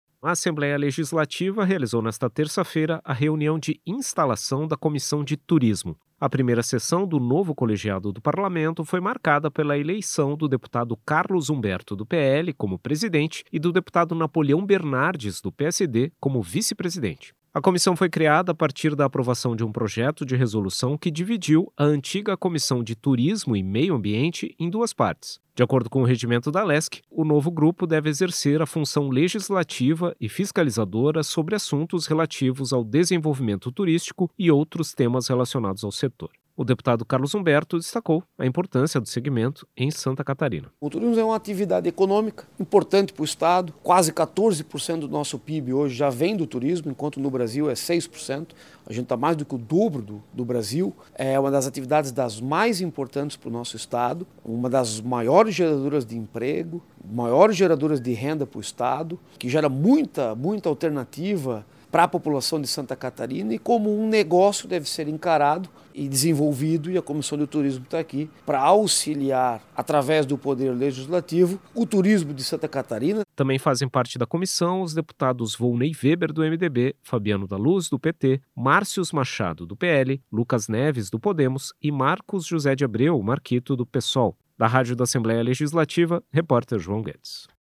Entrevista com:
- deputado Carlos Humberto (PL), presidente da Comissão de Turismo da Assembleia Legislativa.